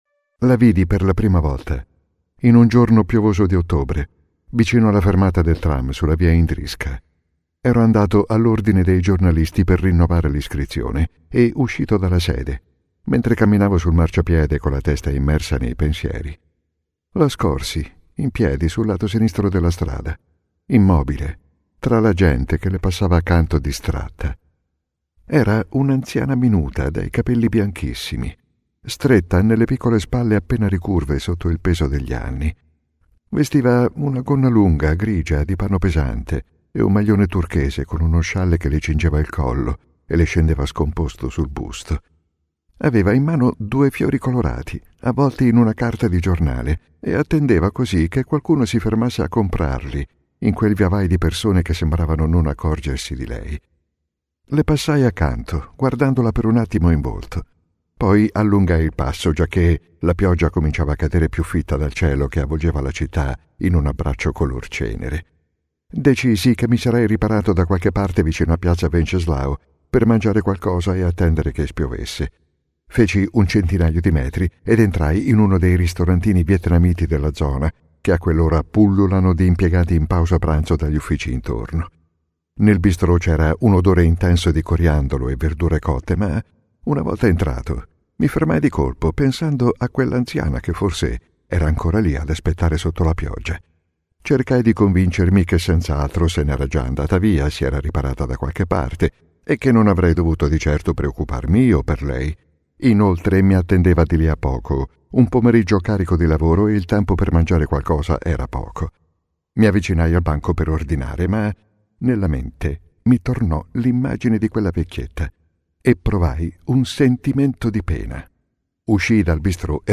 ©2020 audiolibro  (disponibile solo in formato digitale)